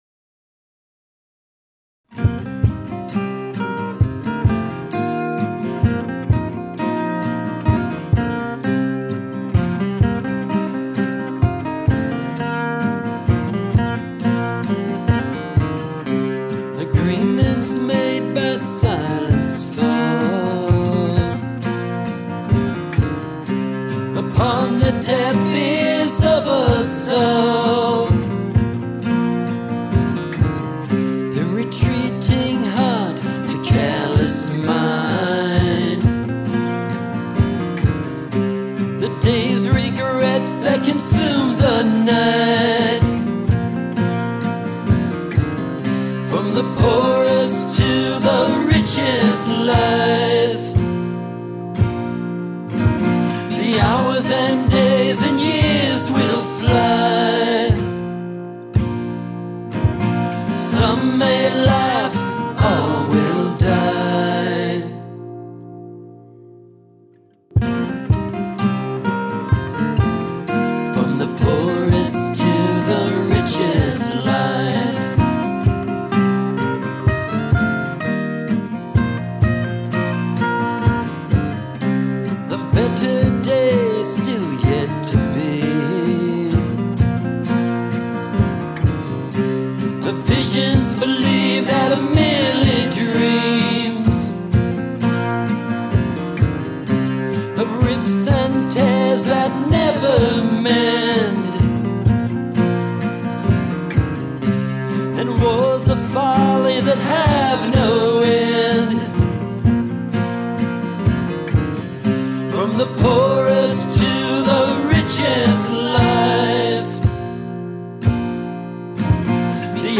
Theremin Stays! were recorded in Chicago at Handwritten